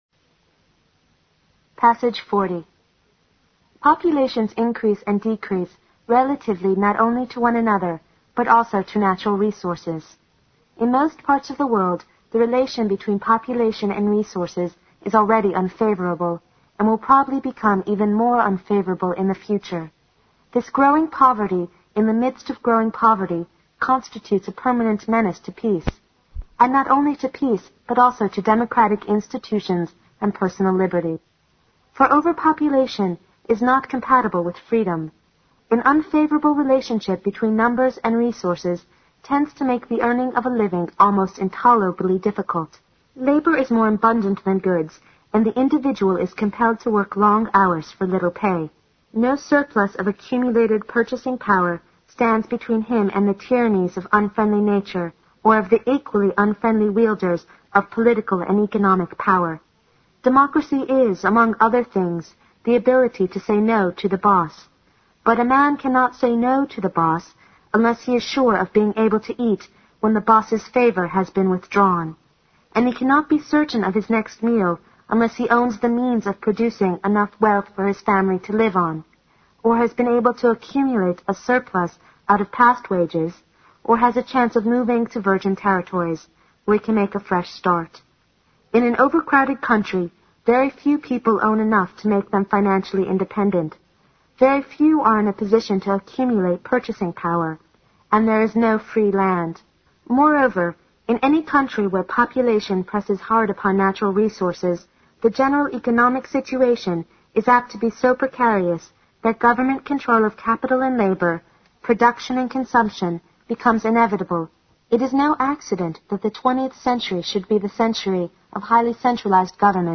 新概念英语85年上外美音版第四册 第40课 听力文件下载—在线英语听力室